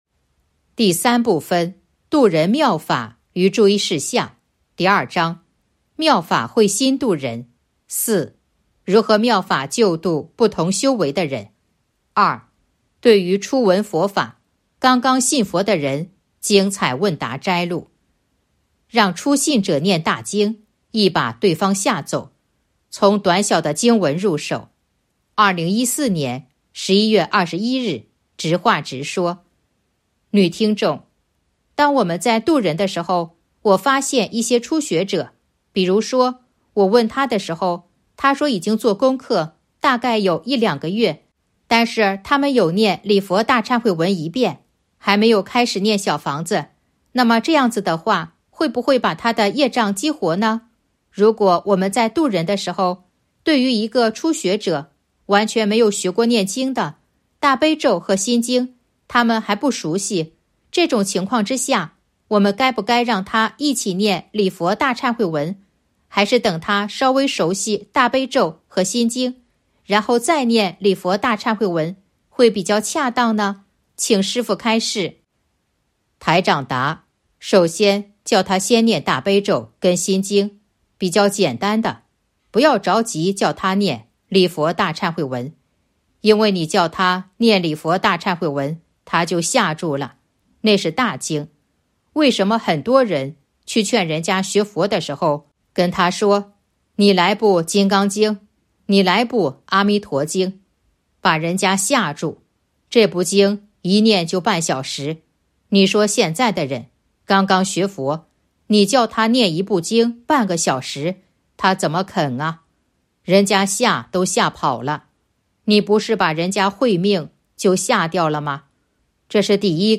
027.（二）对于初闻佛法、刚刚信佛的人精彩问答摘录《弘法度人手册》【有声书】